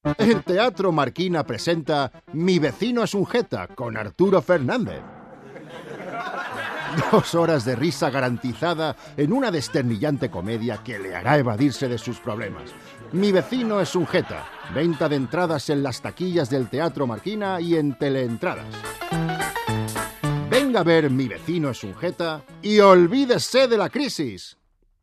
Voz masculina grave con mucha personalidad.
Sprechprobe: Sonstiges (Muttersprache):
Warm and masculine voice, very versatile both for dramatic and comedy roles.